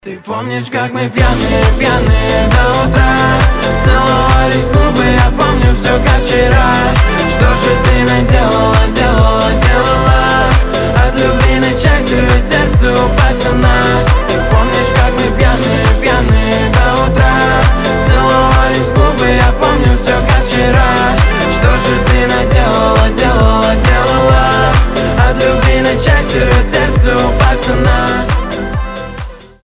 мужской голос
громкие